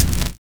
FINE BD    8.wav